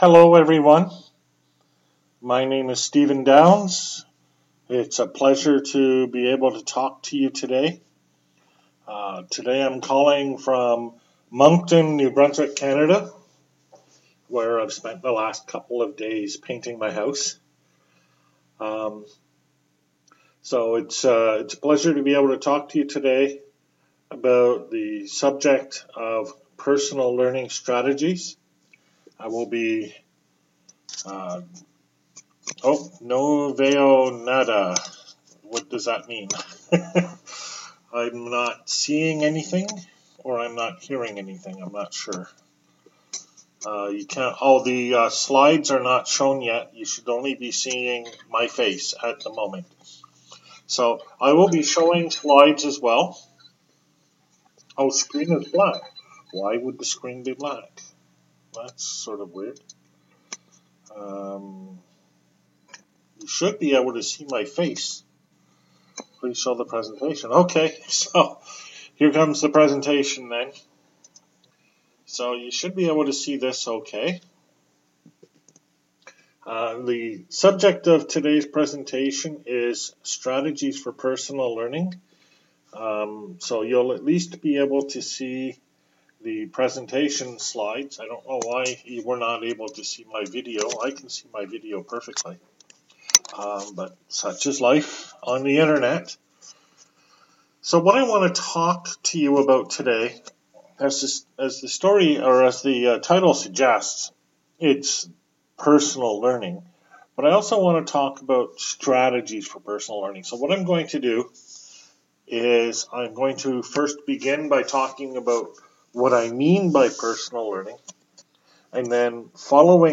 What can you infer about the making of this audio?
online via Zoom, Lecture